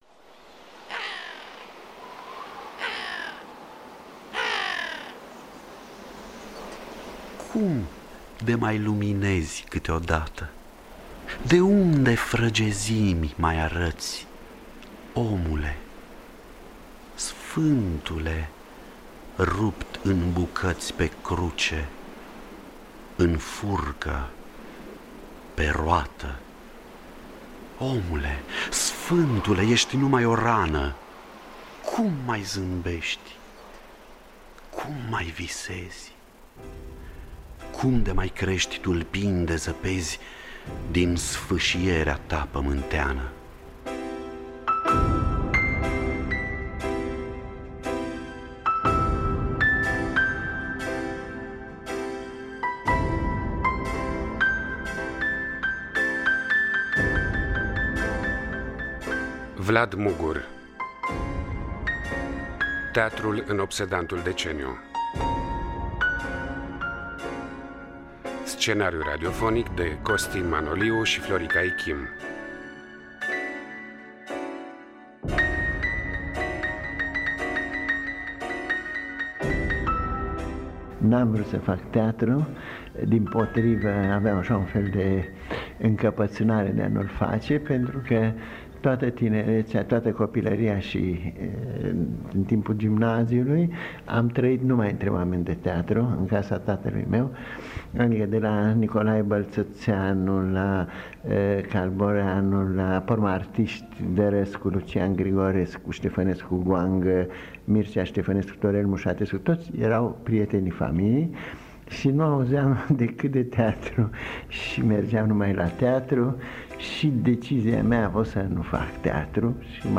Spectacol de teatru-document bazat pe confesiunile regizorului Vlad Mugur. Cu participarea extraordinară a actorilor: Olga Tudorache şi Constantin Codrescu.